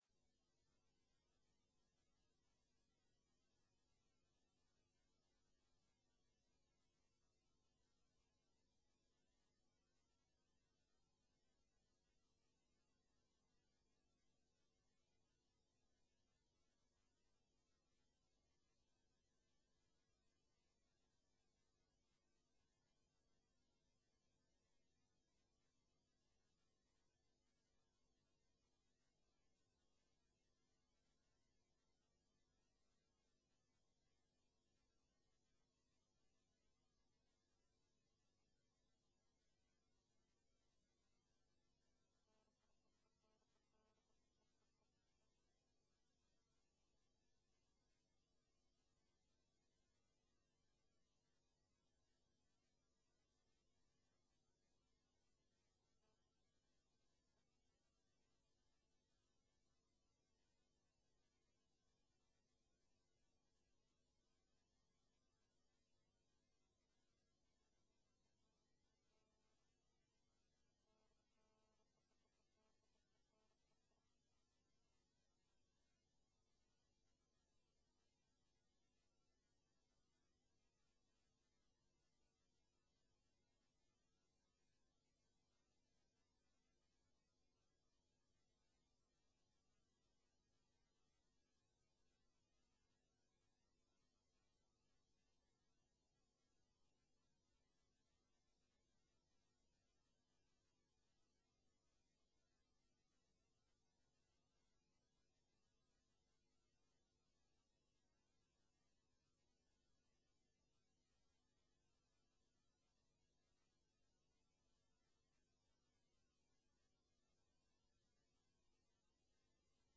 Seduta del Consiglio Comunale del 28/07/2016